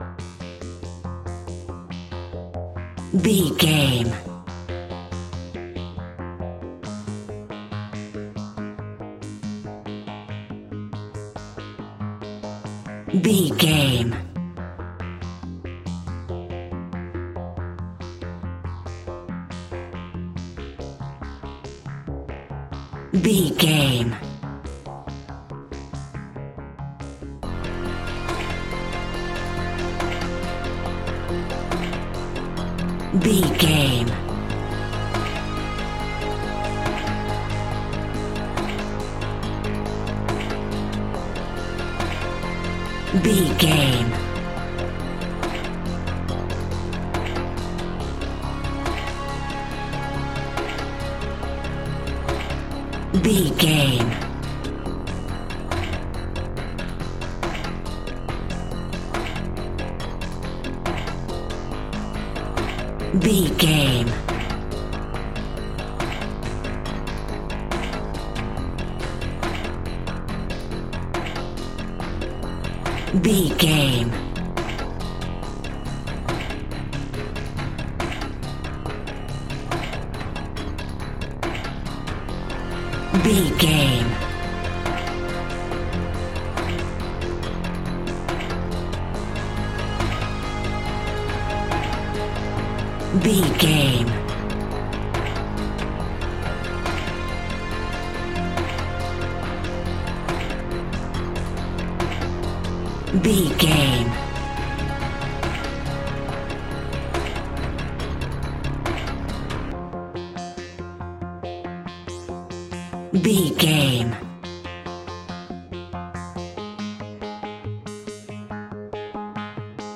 In-crescendo
Thriller
Aeolian/Minor
ominous
dark
haunting
eerie
horror music
Horror Pads
horror piano
Horror Synths